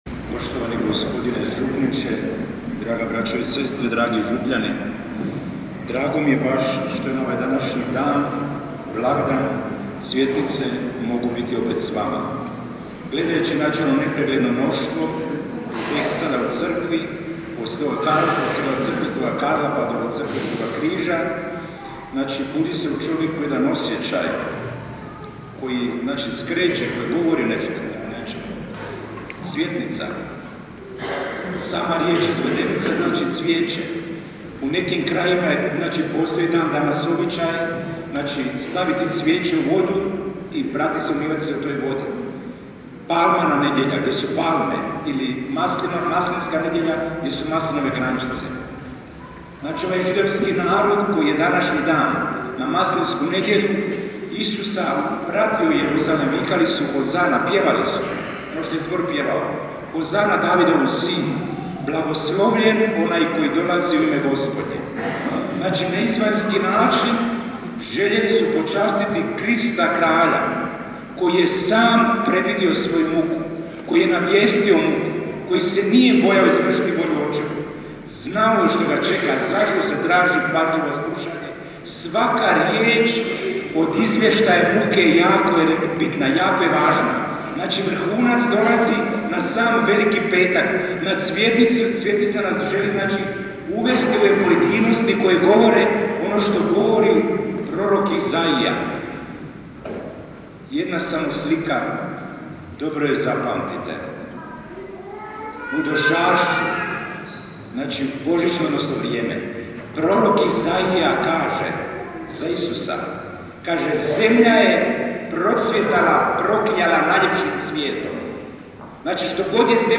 Propovijedi